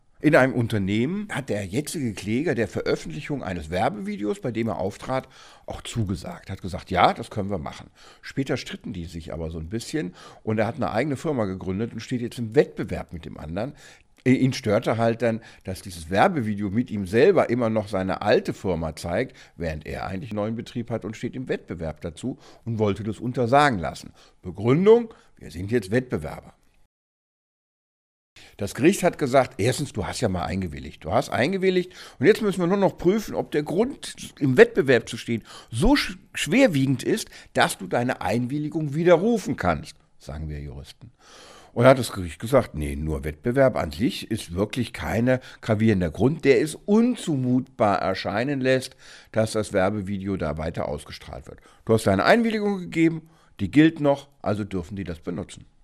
Kollegengespräch: Einwilligung zur Bildveröffentlichung gilt – Vorabs Medienproduktion